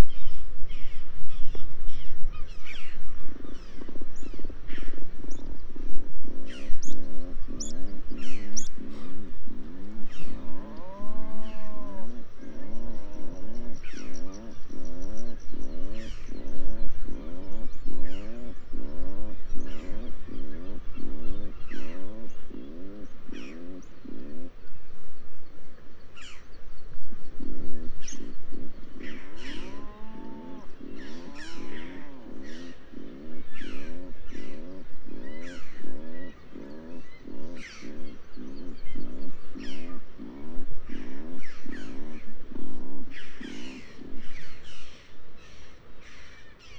Copyright: XC809908 – Atlantic Puffin – Fratercula arctica.